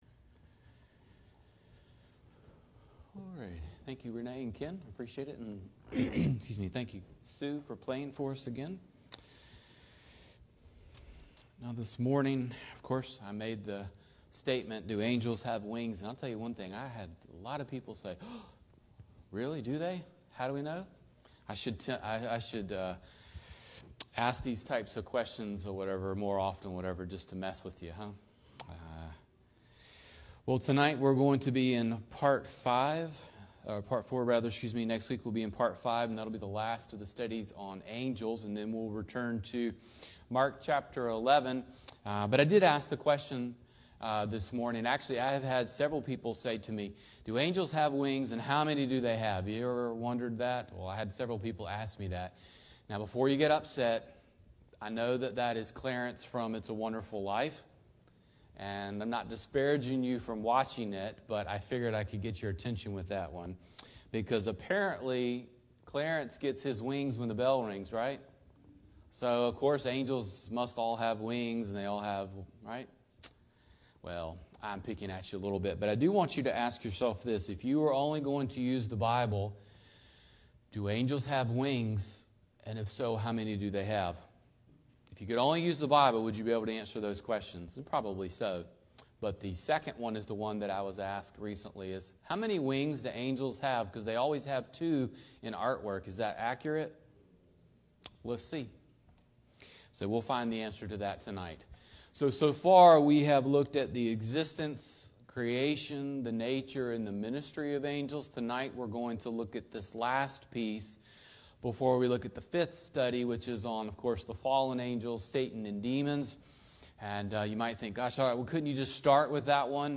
Sermons | Decatur Bible Church